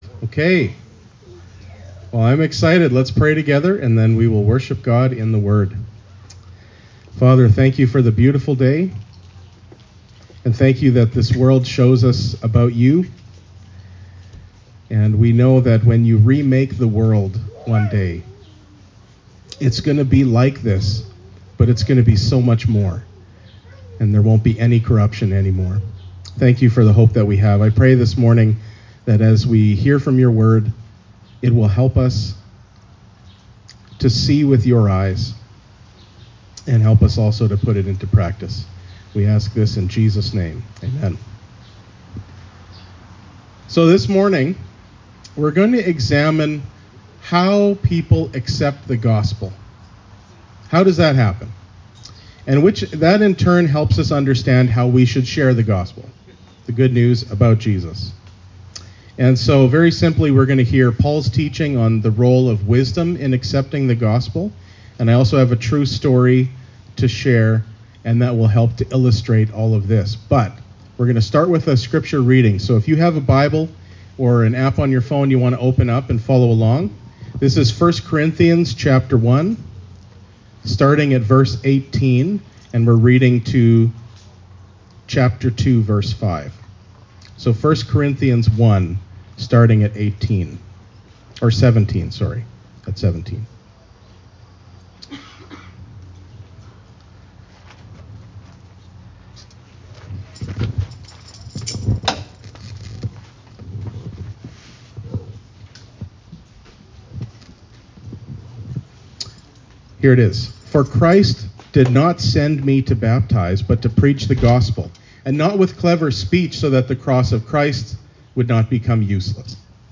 (Please note, this message was shared outside during our Church in the Park event.) When we understand how people hear the good news about Jesus, that in turn helps us to understand how we should be sharing that good news with others.